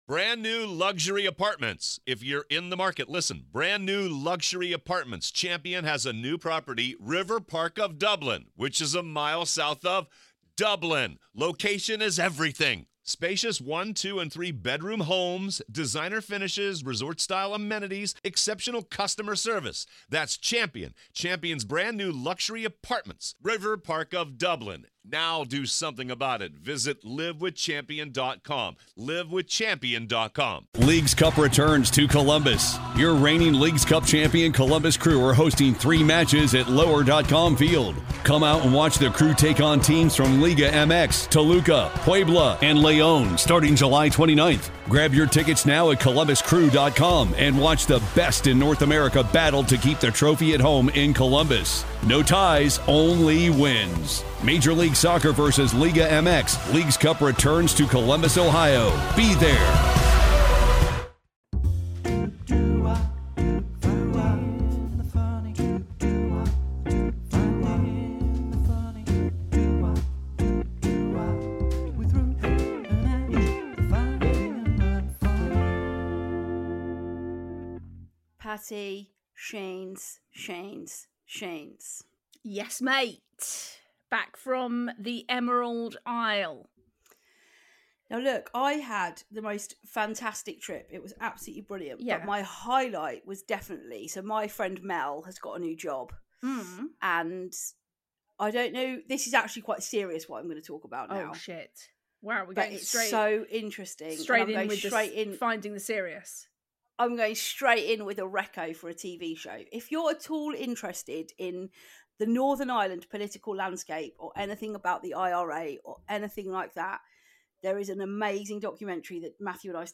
which features a new jingle (brilliant) to go with our new segment